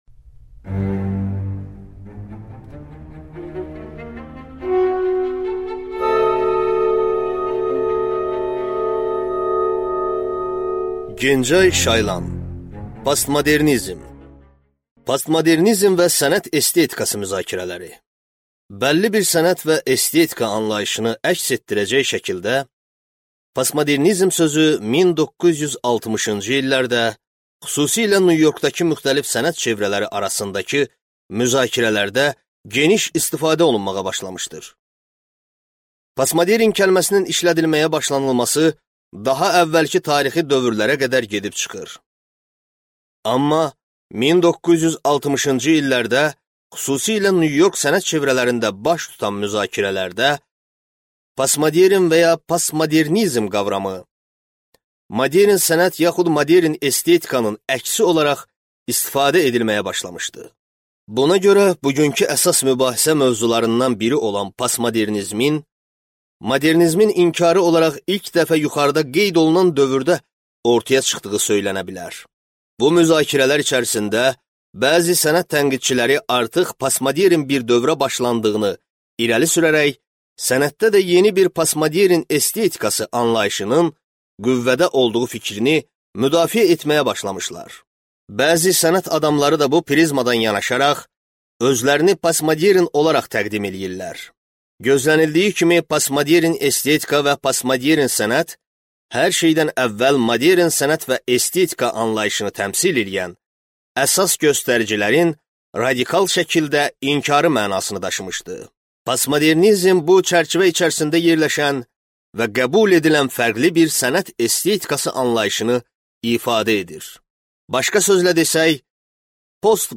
Аудиокнига Postmodernizm | Библиотека аудиокниг